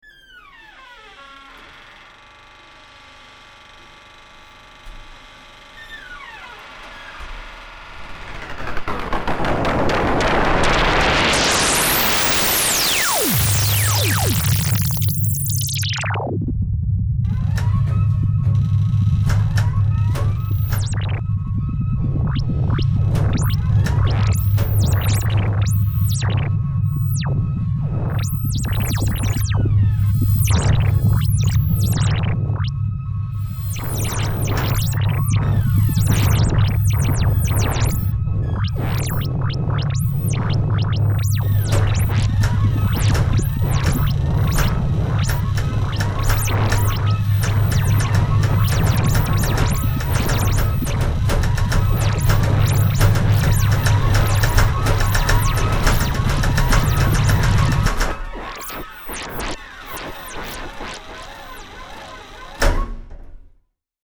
4-track electroacoustic